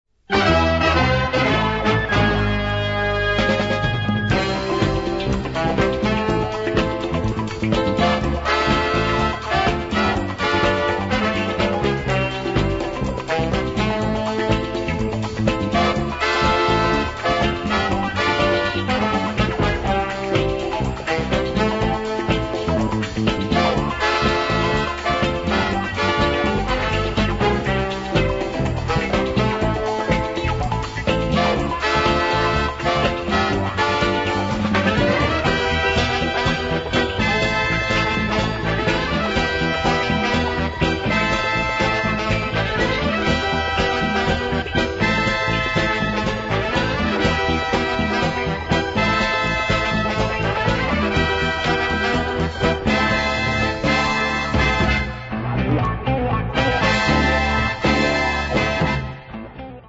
The music's equally weird!
and is suitably camp!